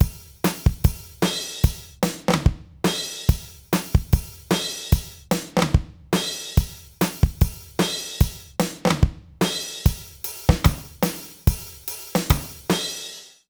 British ROCK Loop 136BPM.wav